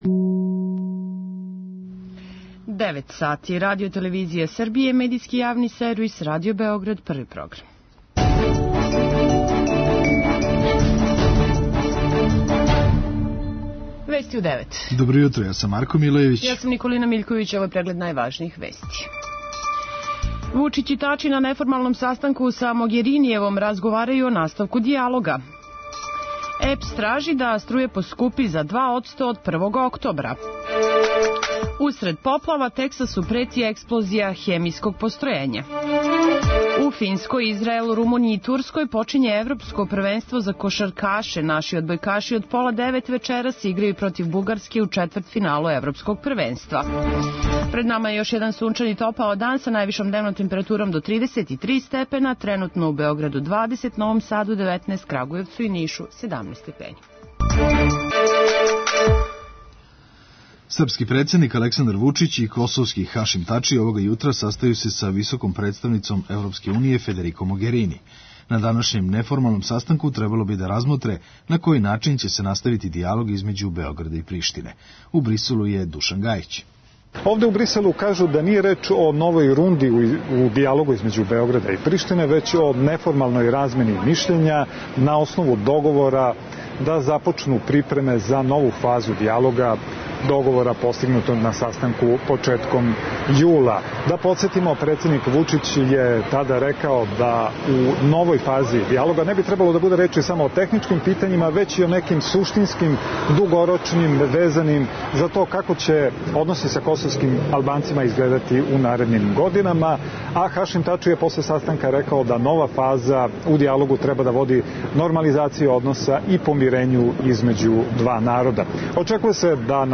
преузми : 3.32 MB Вести у 9 Autor: разни аутори Преглед најважнијиx информација из земље из света.